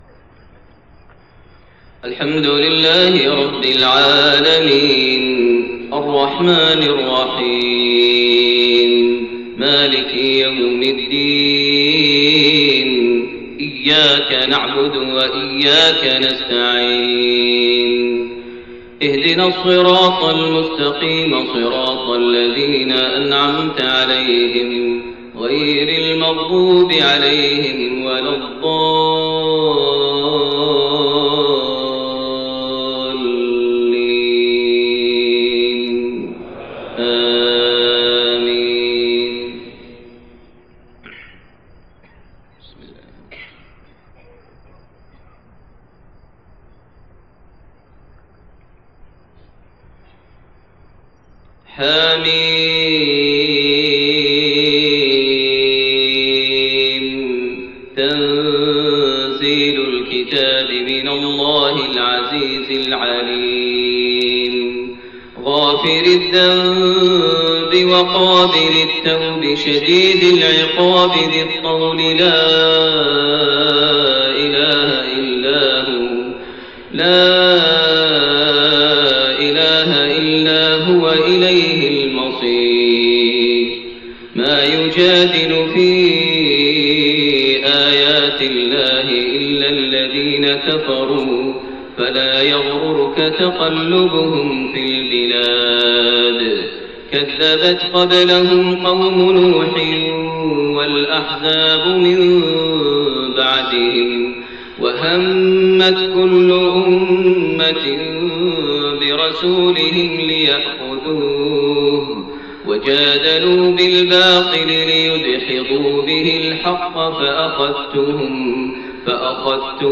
صلاة الفجر 1-4-1431 من سورة غافر 1-20 > 1431 هـ > الفروض - تلاوات ماهر المعيقلي